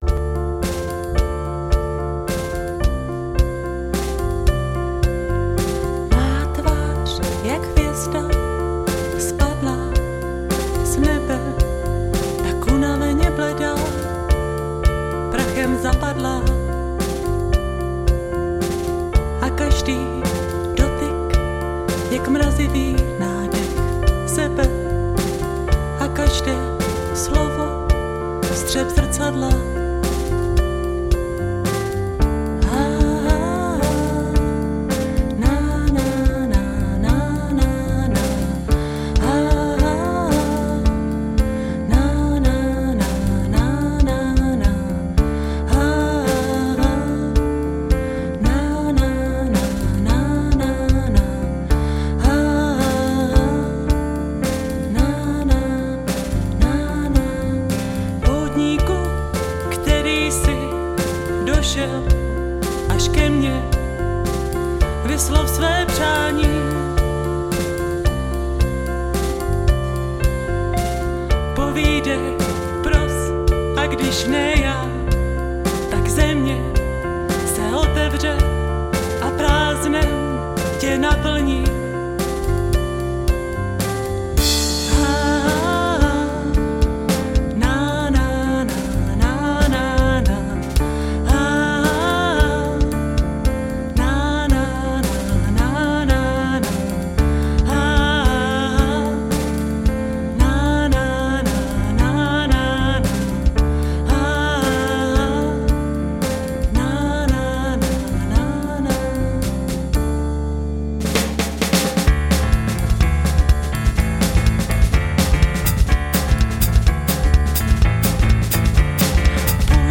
Žánr: World music/Ethno/Folk